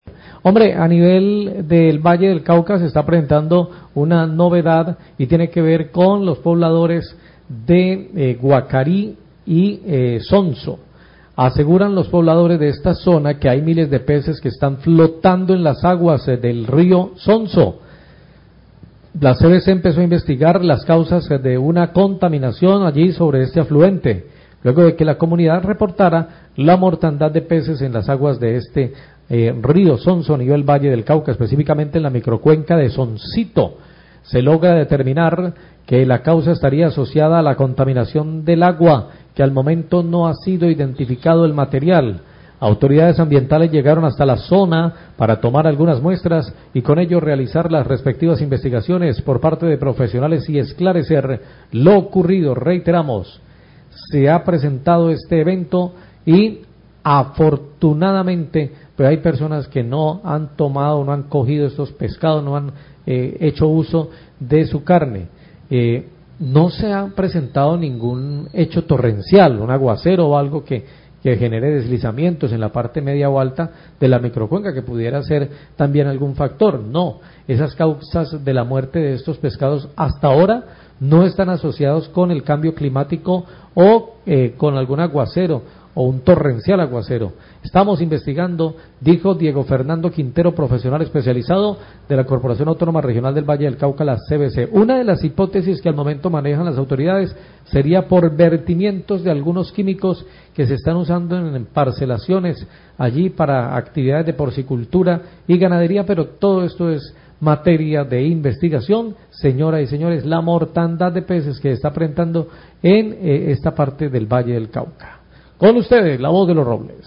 Radio
reporte cvc